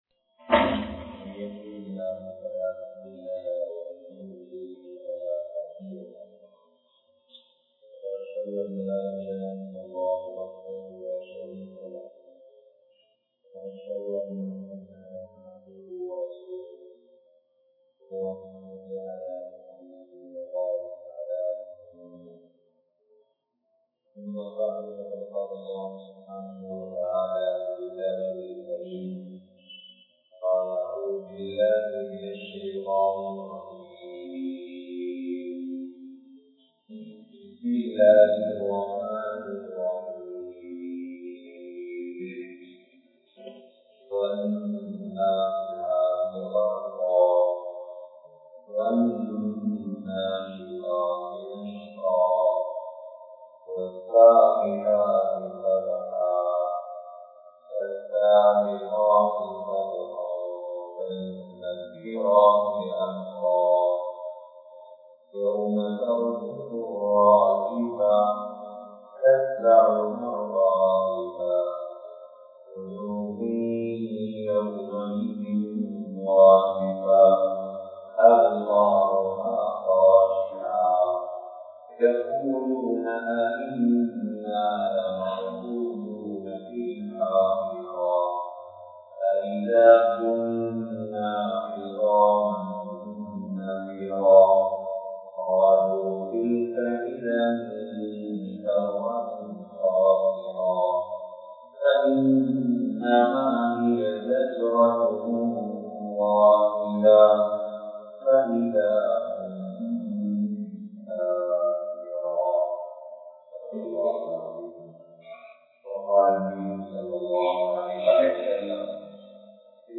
05 Vahaiyaana Malakkuhal (05 வகையான மலக்குகள்) | Audio Bayans | All Ceylon Muslim Youth Community | Addalaichenai